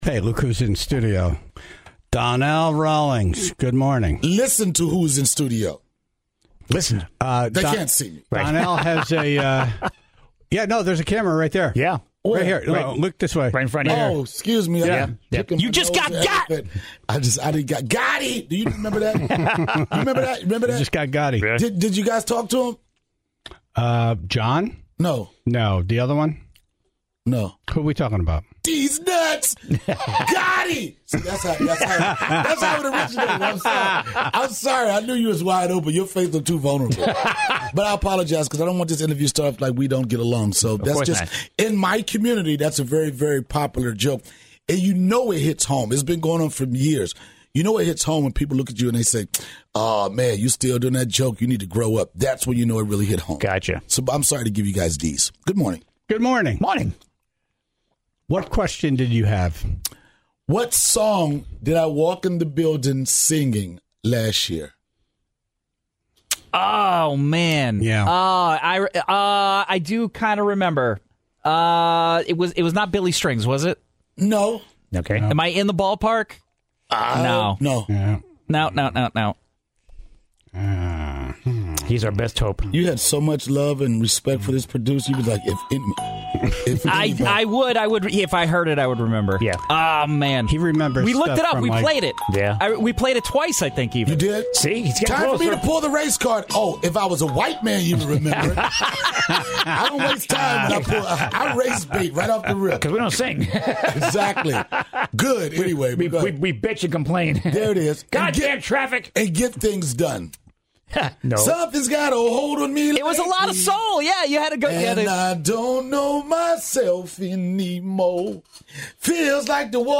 in studio this morning